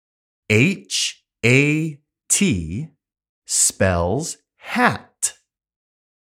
単語の読み方・発音